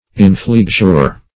Inflexure \In*flex"ure\, n.